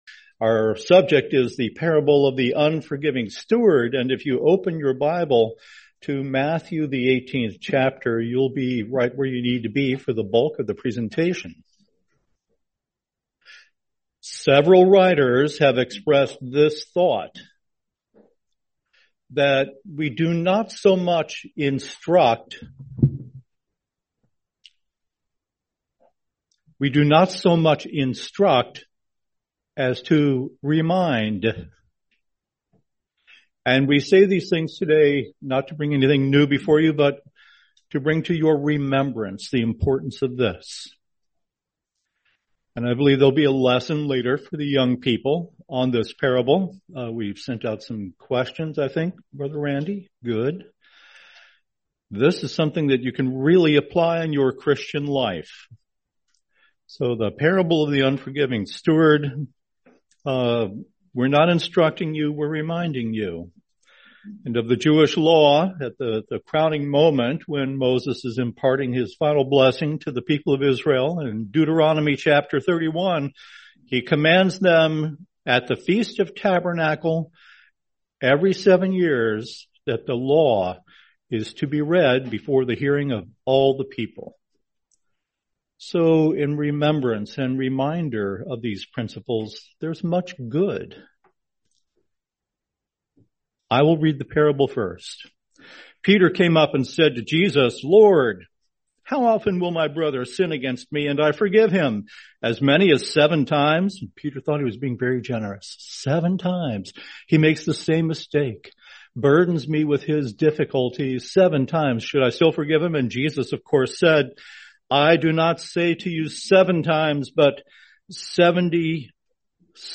Series: 2025 Sacramento Convention